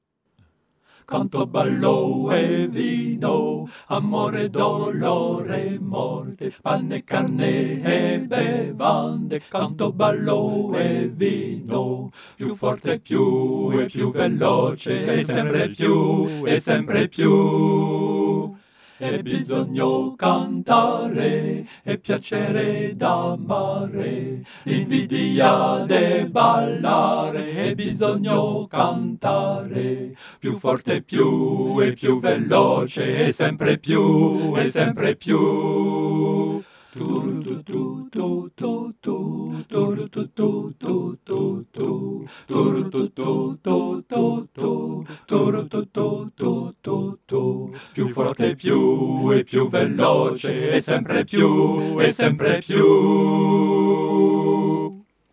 A 4 vx
Cette chanson doit rester légère,